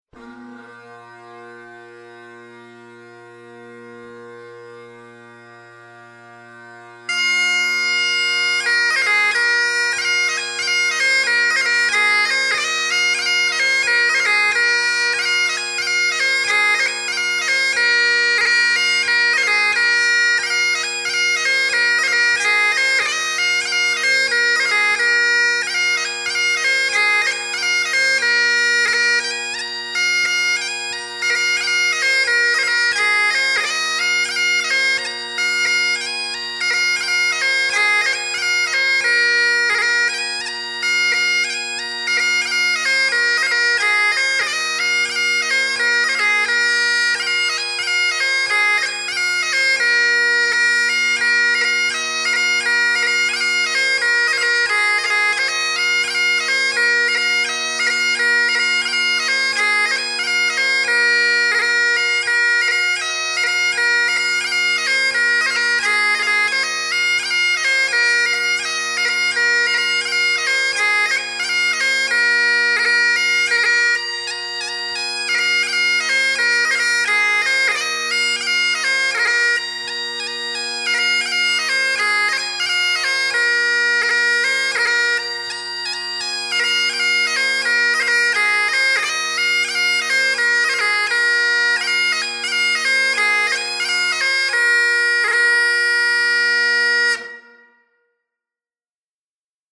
Scottish and Irish Bagpipe Music
Pipe Major Donald MacLean – March